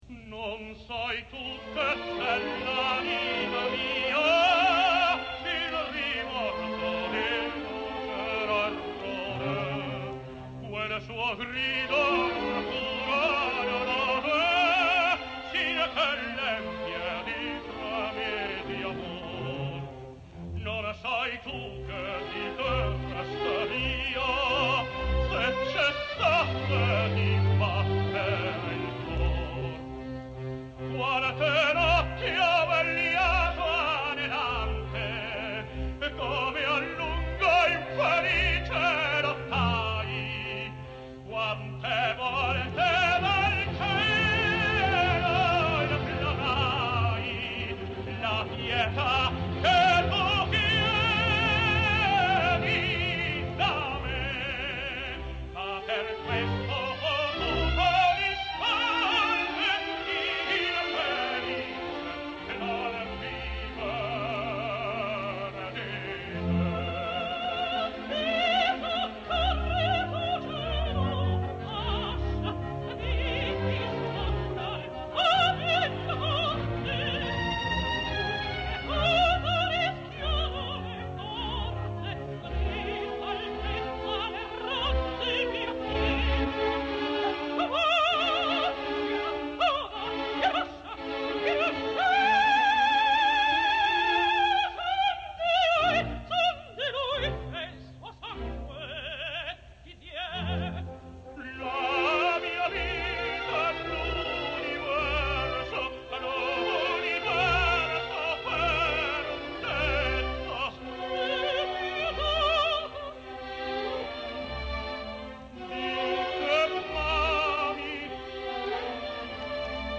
opera completa, registrazione in studio.